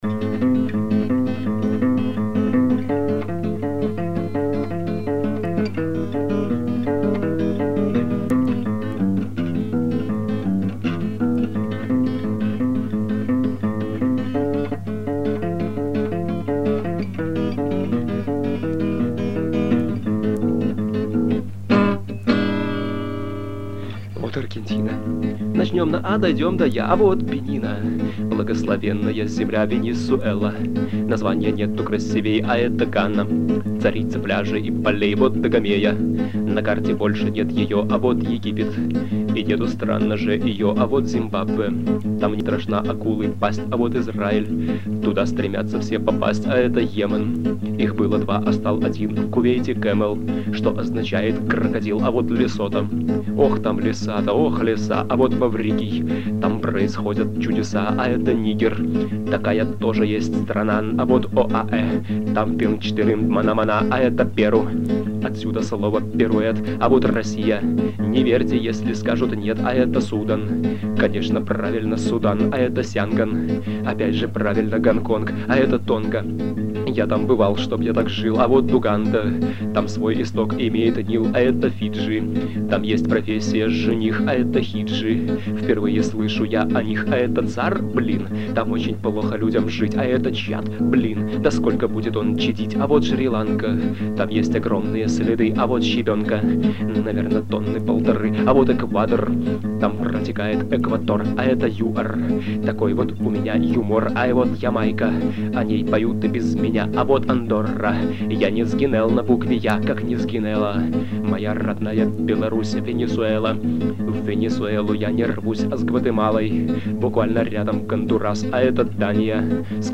1996 г., Гомель, соло
Домашняя акустика
Качество записи - так себе